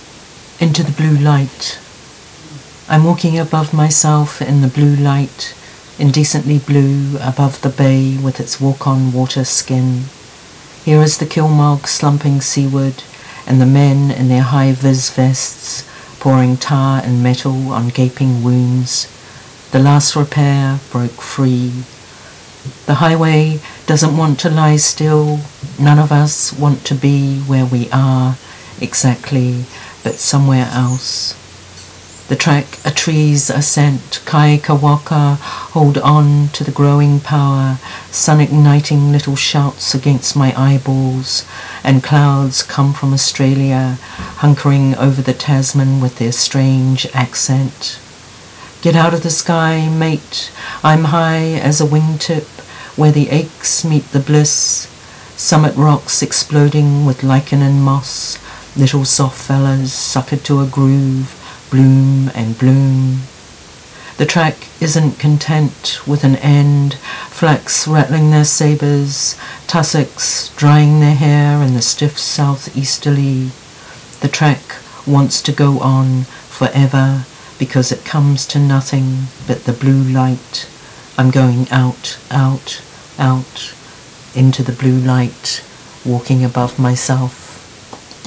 reads ‘Into the Blue Light’